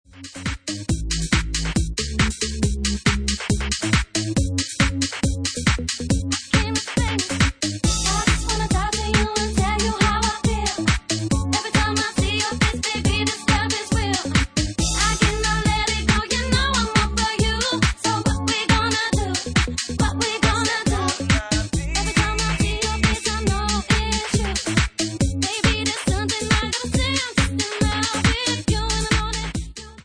Bassline House at 138 bpm